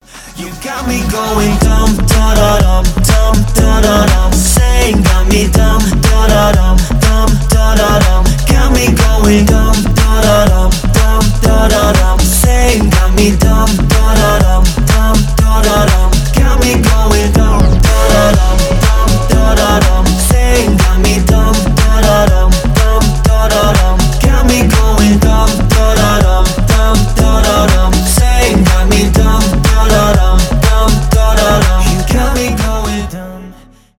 house
танцевальные